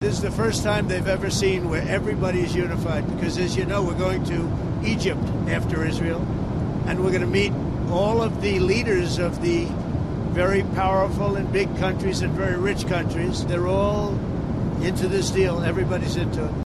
Speaking on Air Force One, he says getting all sides to back his deal was a major achievement: